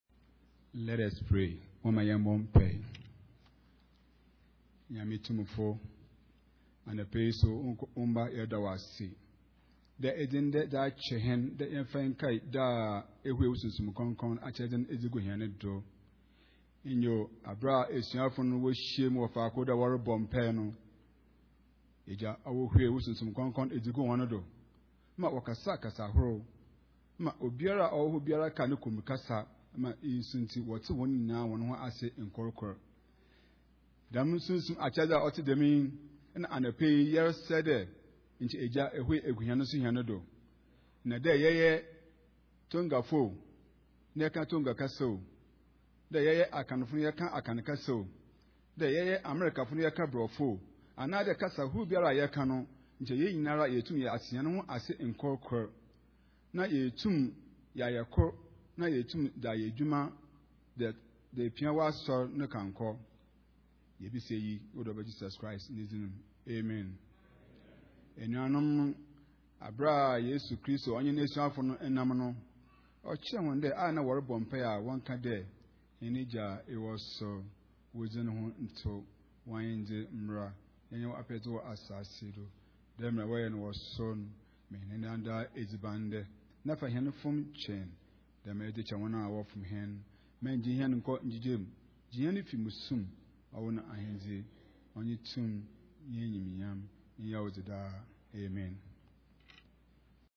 Pentecost Sunday Worship Service
Pentecost Prayer